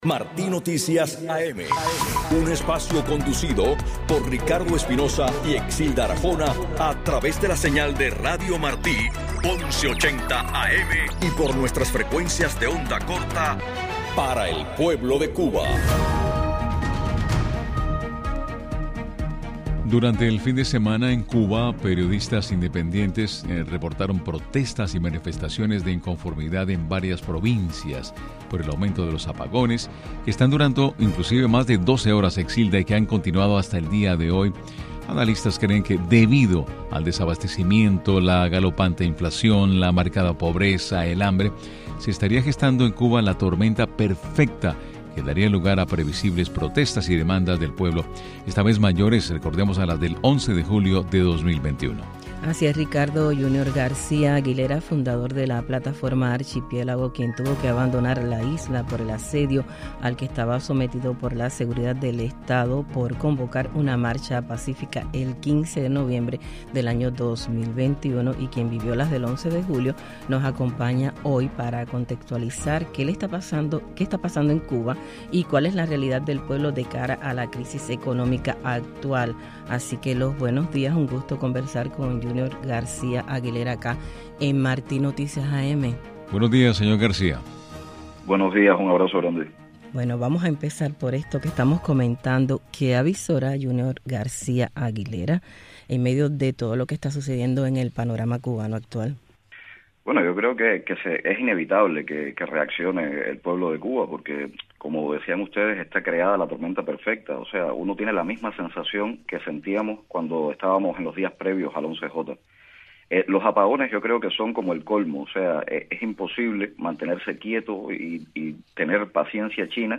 Declaraciones del activista y dramaturgo Yunior García Aguilera al informativo Martí Noticias AM